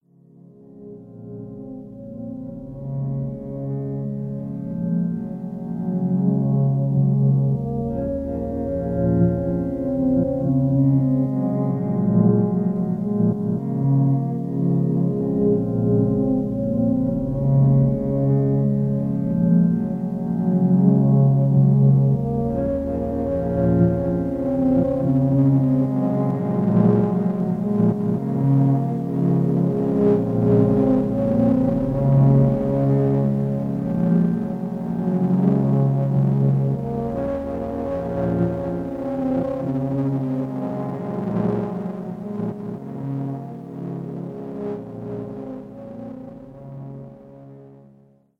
I didn't mean for this to come out sounding like Tim Hecker.